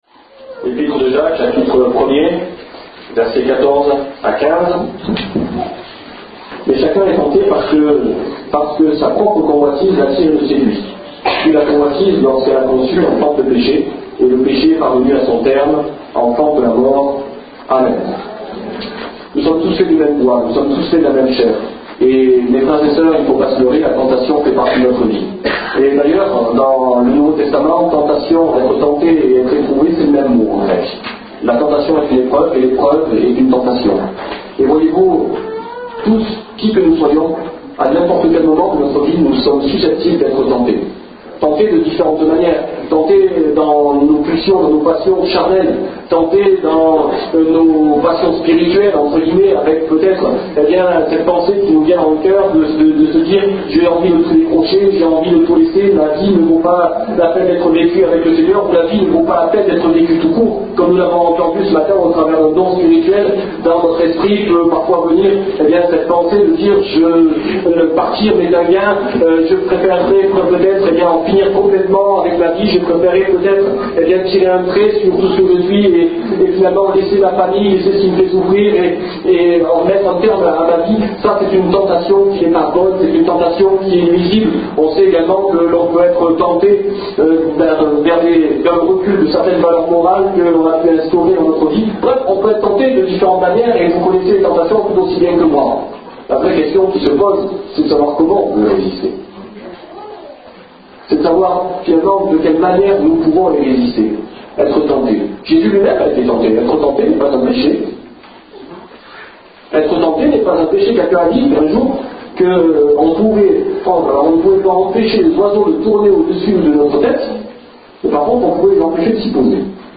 Message audio Download « La tentation » Leave a Reply Name